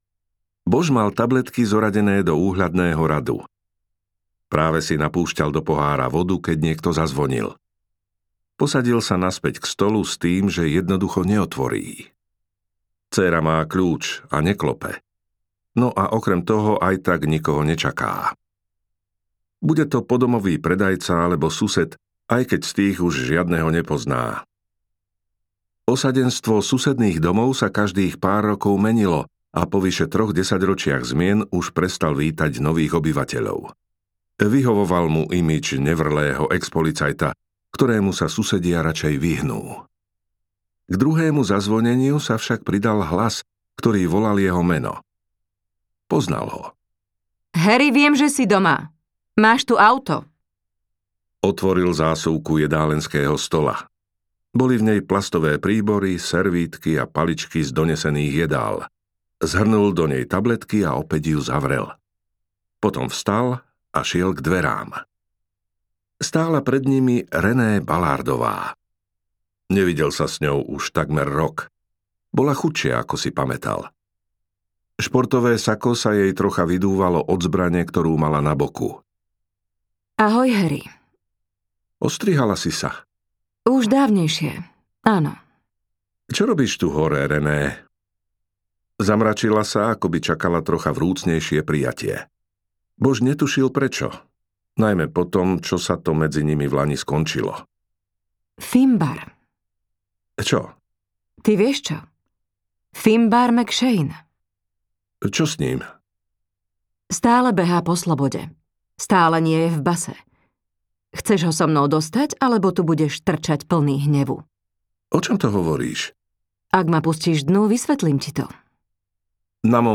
Púštna hviezda audiokniha
Ukázka z knihy